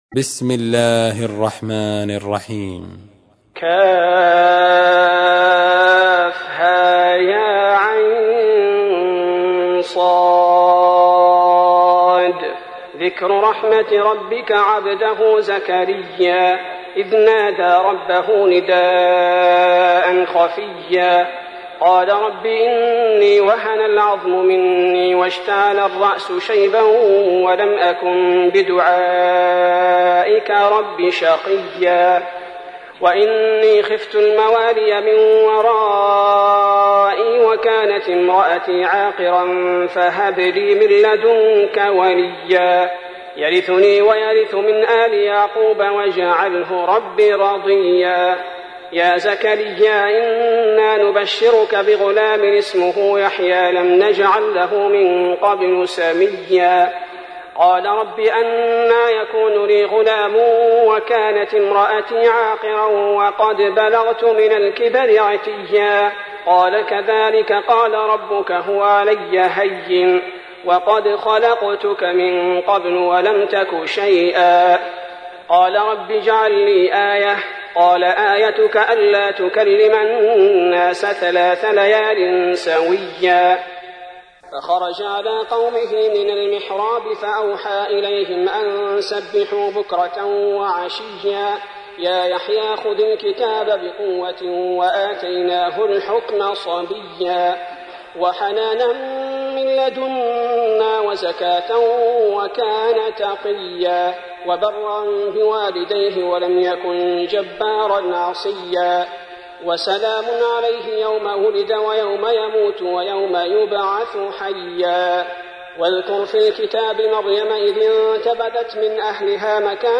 تحميل : 19. سورة مريم / القارئ عبد البارئ الثبيتي / القرآن الكريم / موقع يا حسين